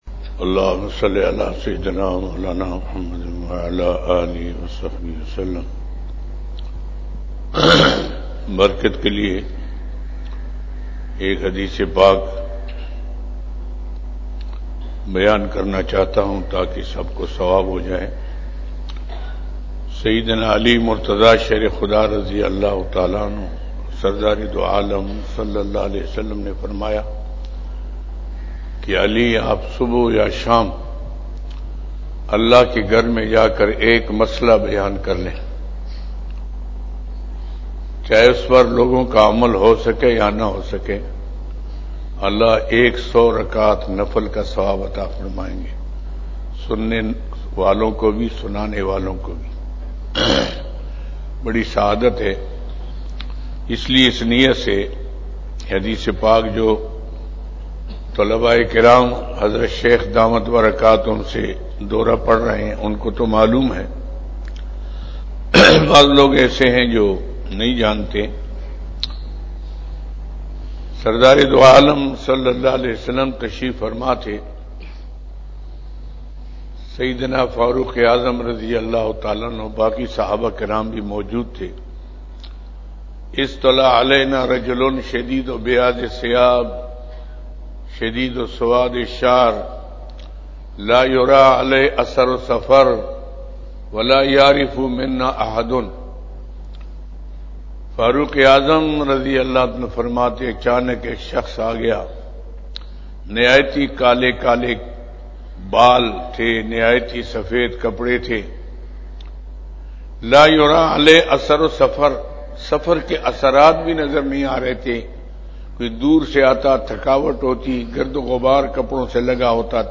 بیان بعد نماز فجر بروز بدھ 26 جمادی الاول 1441ھ/ 22 جنوری 2020ء"